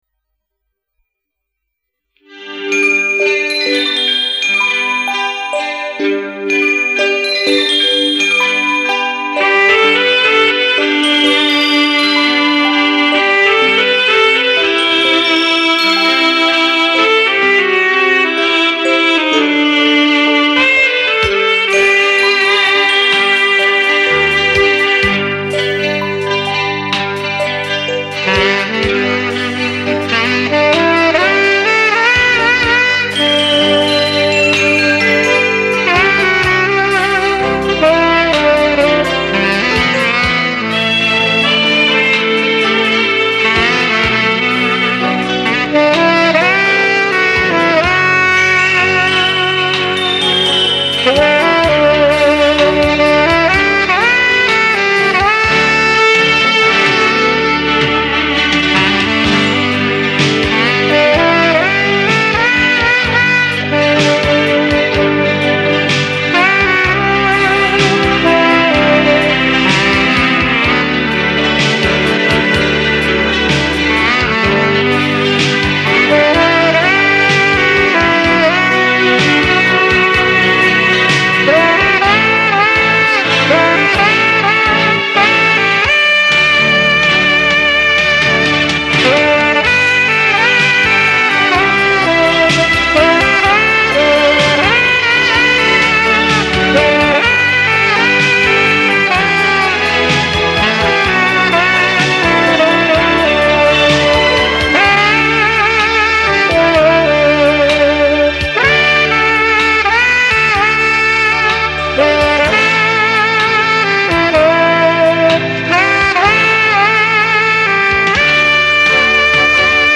著名萨克斯演奏家。